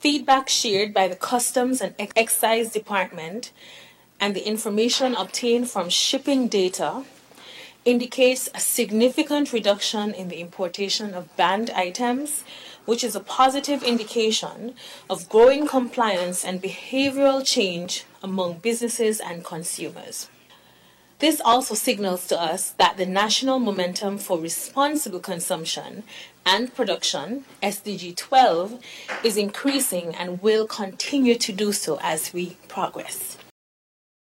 The May 15th, 2025 sitting of the National Assembly featured Federal Minister of Environment, the Hon. Dr. Joyelle Clarke discussing the ongoing phased ban on Single Use Plastics: